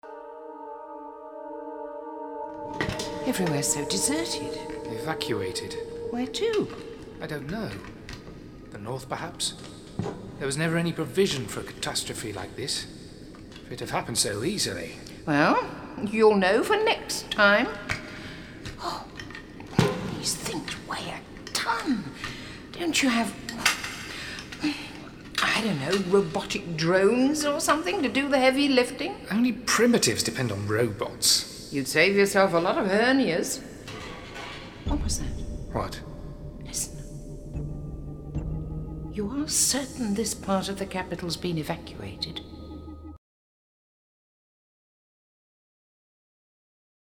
Drama